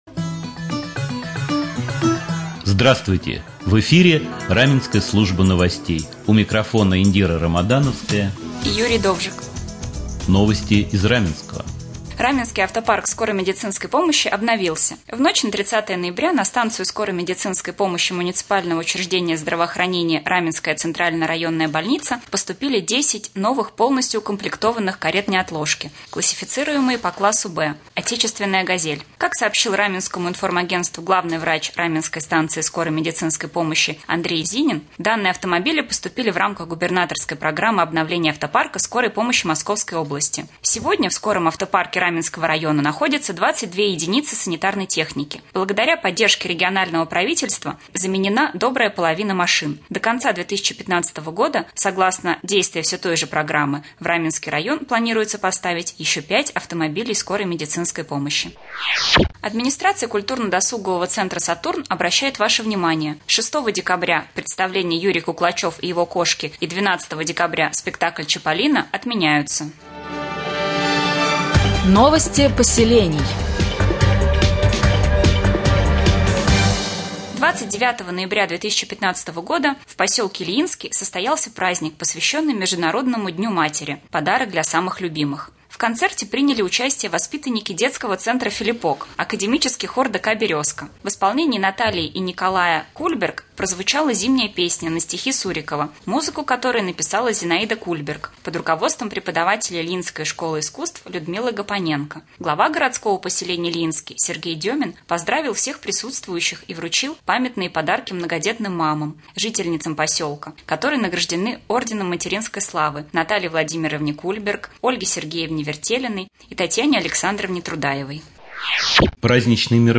3. Новости